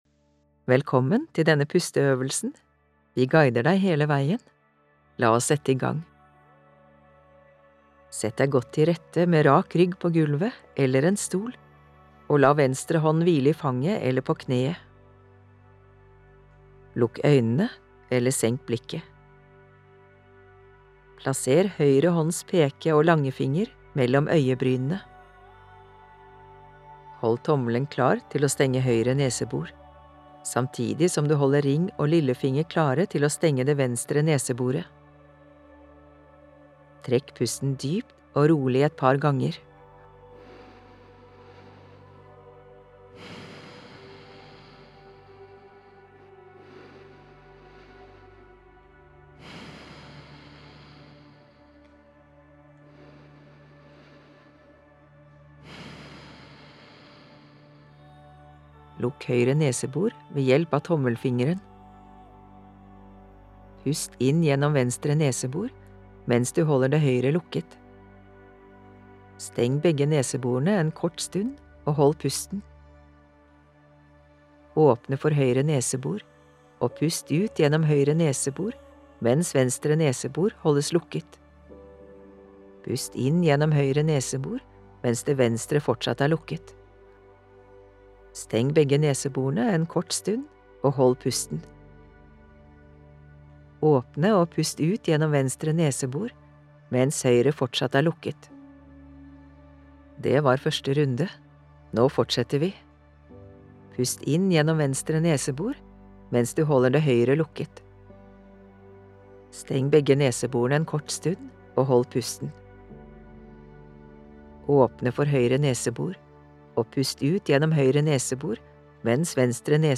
Vekselpust – pusteøvelse med lydguide
• Du gjør i alt 5 runder av øvelsen og blir guidet til hver runde.